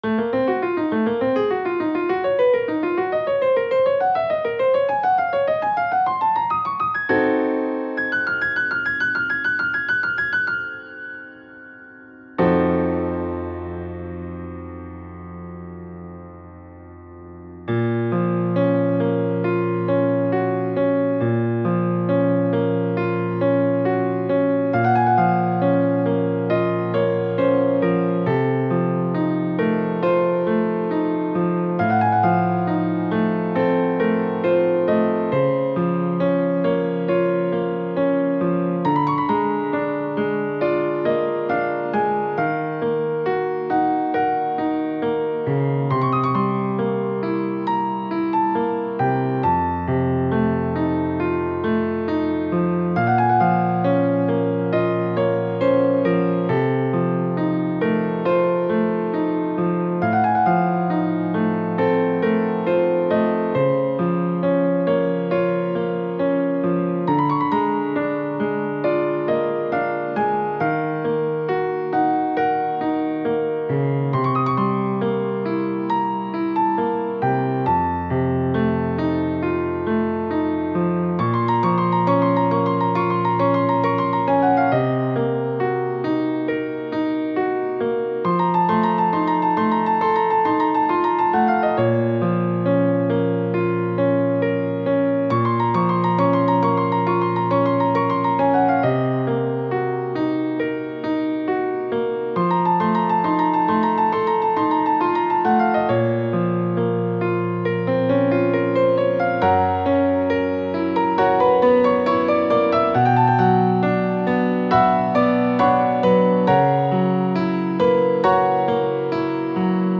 армянский пианист